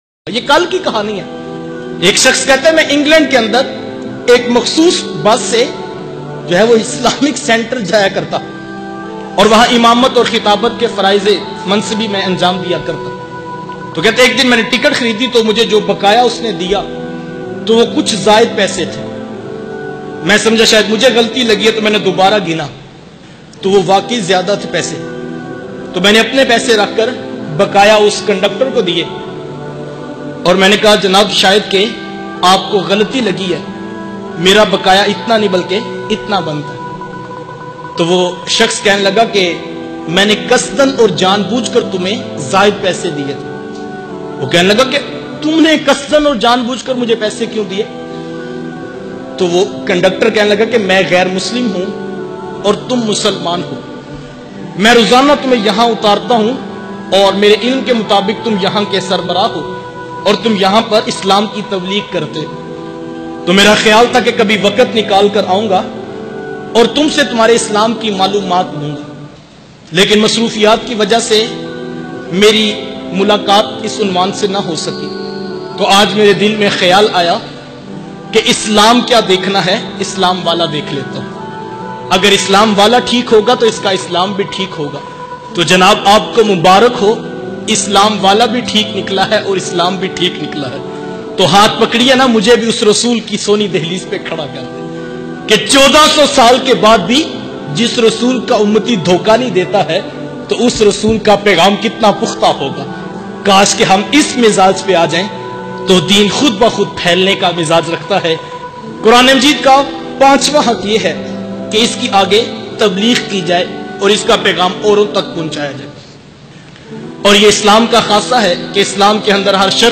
England me gair muslim ka iman lana bayan mp3